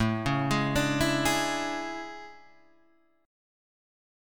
A Major 11th